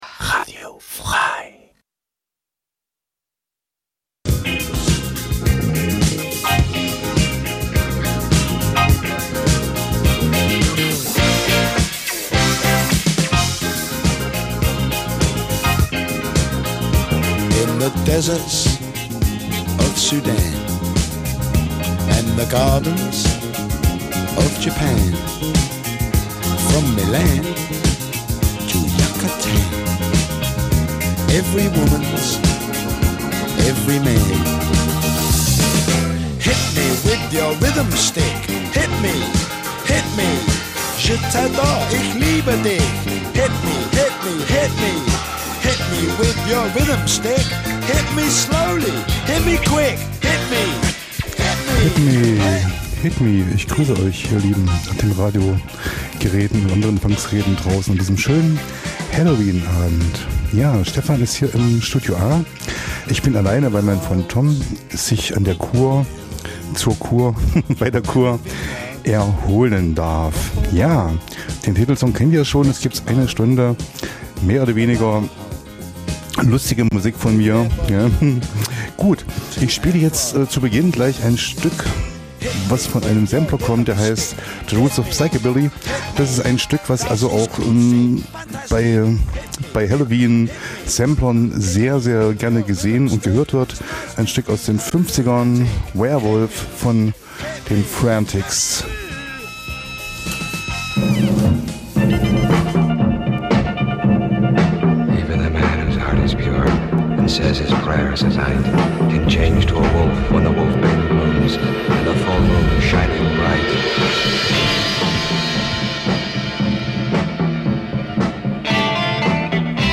Zwei Musikfreunde duellieren sich mit raren Rock- und Punklegenden... - vinyl meets mp3... crossover zwischen den Welten, Urlaubsmusik und Undergroundperlen.
Einmal im Monat Freitag 21-22 Uhr Live on Air und in der Wiederholung montags drauf 12 Uhr.